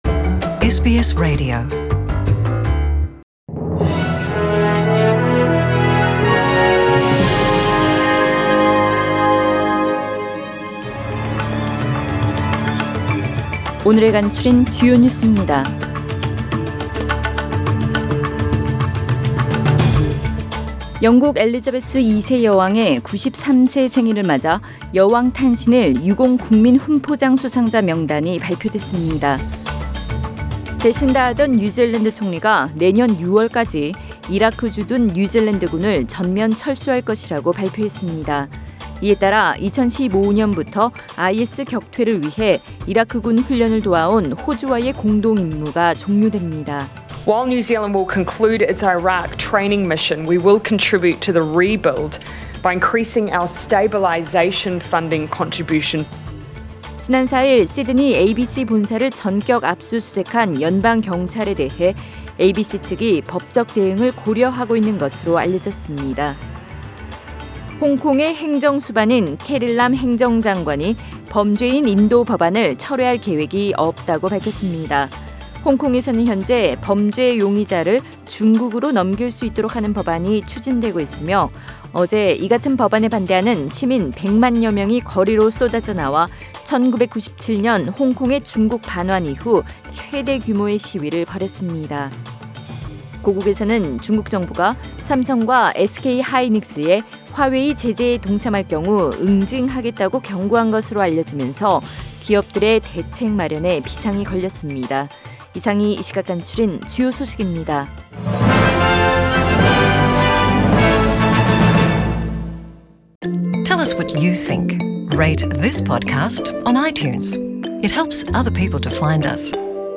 SBS 한국어 뉴스 간추린 주요 소식 – 6월 10일 월요일
2019년 6월 10일 월요일 저녁의 SBS Radio 한국어 뉴스 간추린 주요 소식을 팟 캐스트를 통해 접하시기 바랍니다.